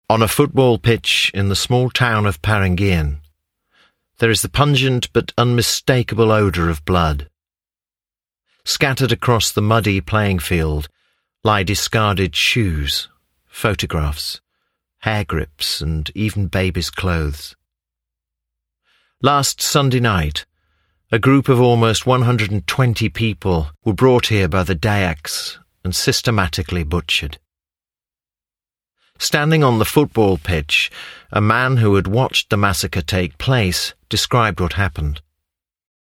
Documentary
Professional male voiceover with gravitas for football stadium massacre